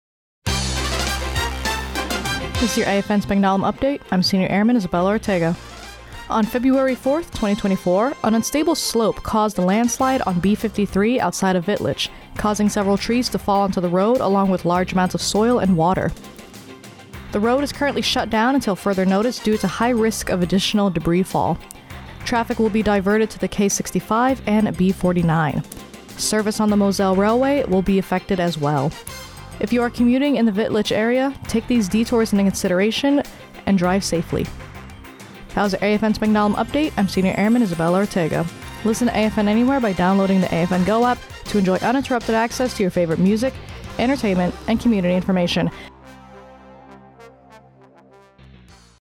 Radio news.